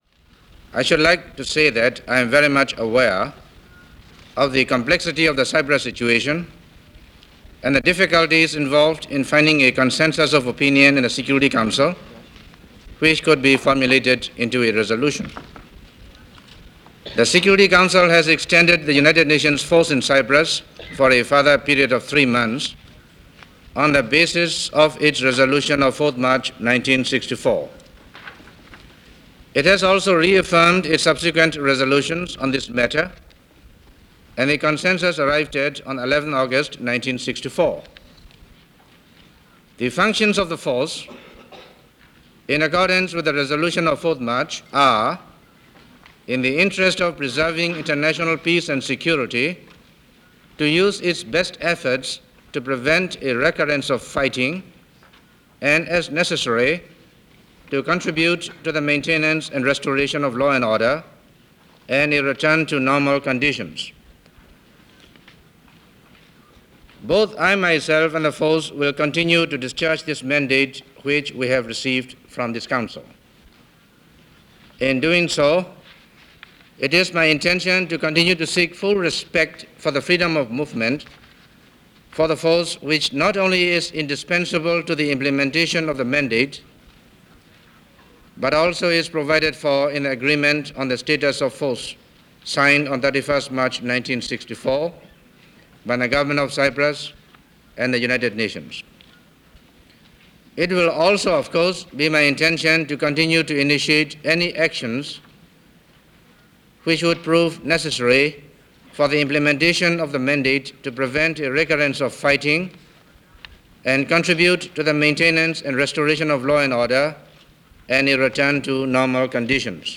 Cyprus: United Nations Extends Peacekeeping Force - September 25, 1964 - Secretary General U Thant Addressing Security Council.
United Nations General Assembly – Secretary General U Thant – Statement On Cyprus – September 25, 1964.